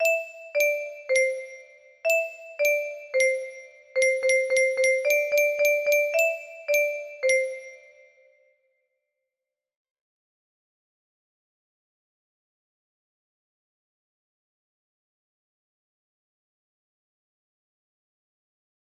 Cold Stars - Hot Cross Buns music box melody